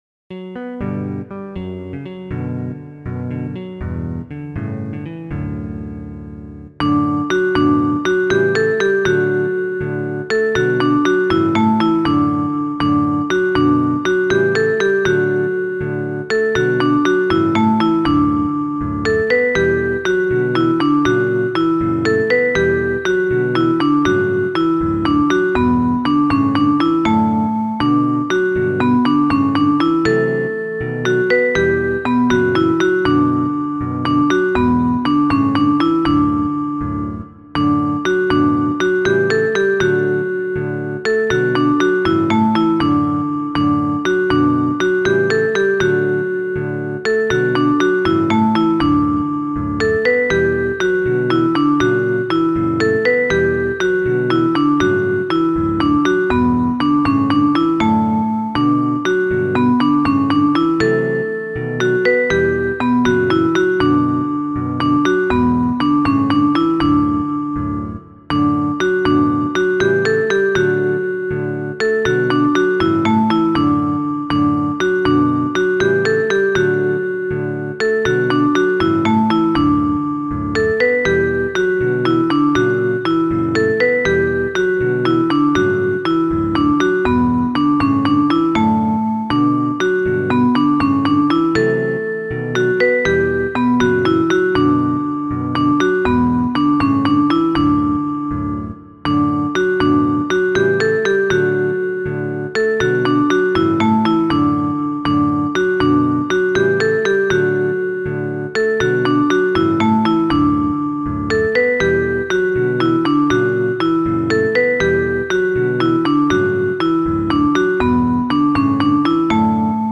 kanto